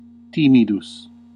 Ääntäminen
US : IPA : [ʃaɪ]